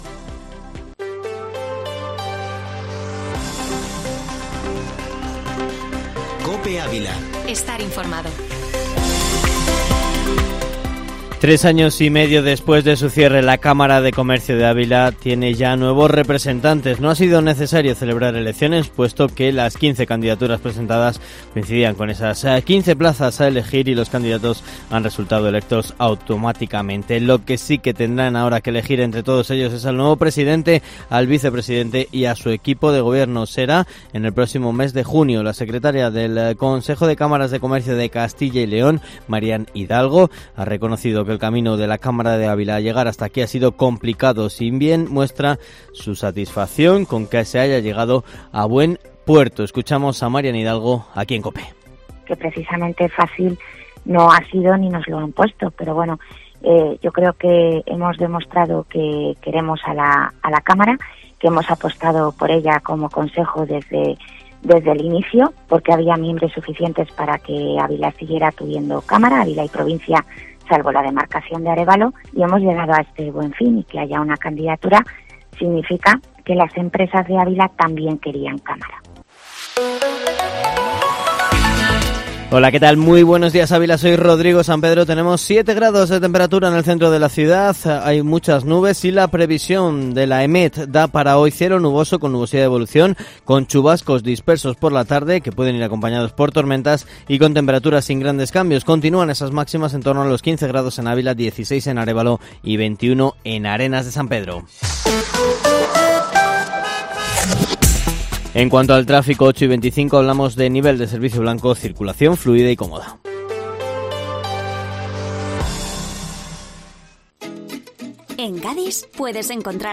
Informativo Matinal Herrera en COPE Ávila -28-abril